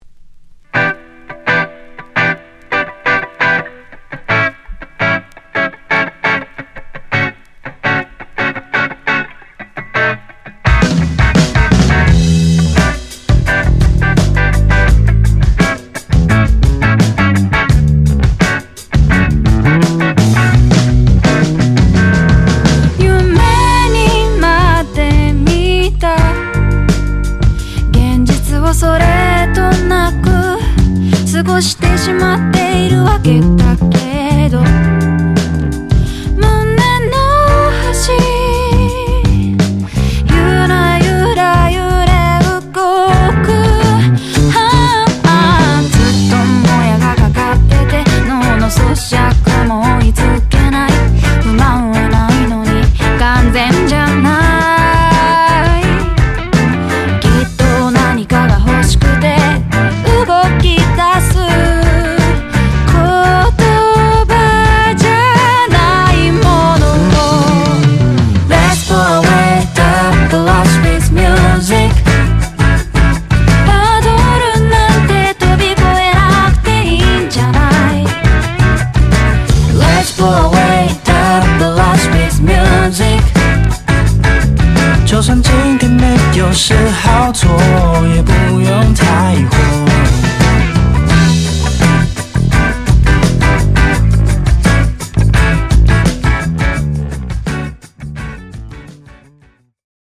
メンバー全員2002年生まれの4人組バンド。